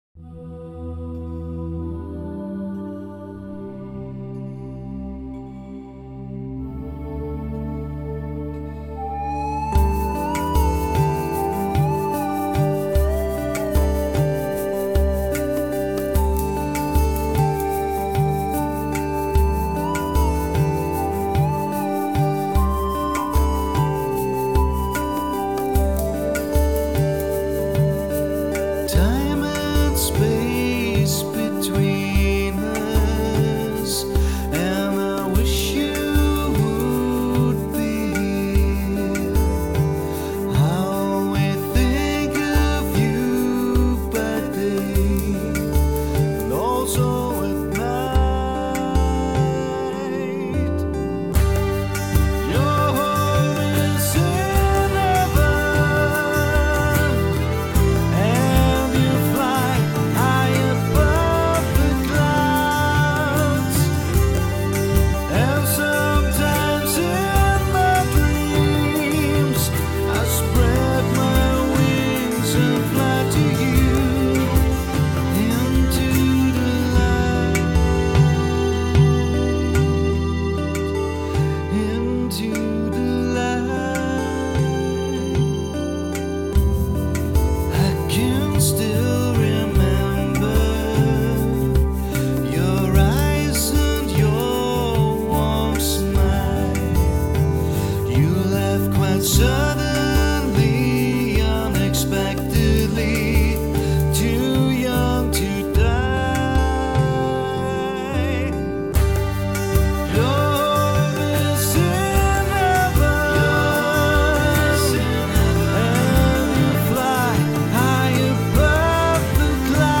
NeoProgRock
Drums
Bass
Keys & Samples
Guitars
Vocals & Keys